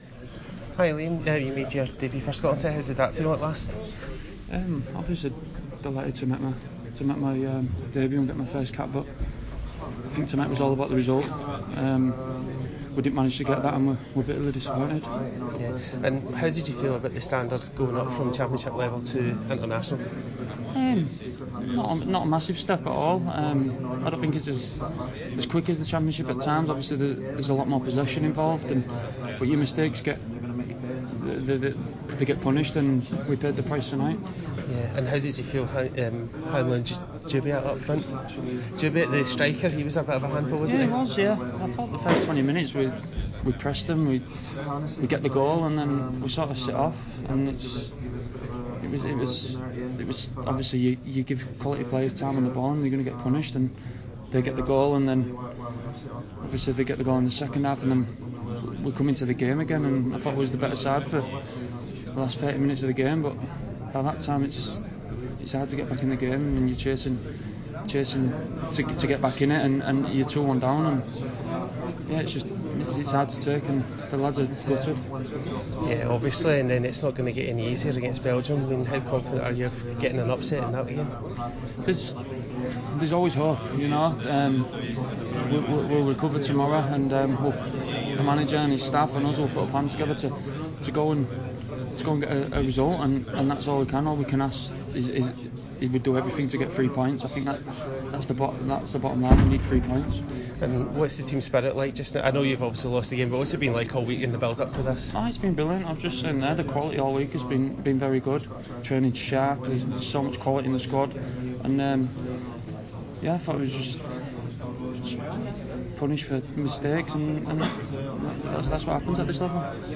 You can hear the disappointment in his voice by clicking the link to the interview at the bottom of this blog.
Liam Cooper Interview
Liam Cooper was speaking to Tartan Army Magazine, where the full will be published in the next edition.
1d021-cooper-interview.mp3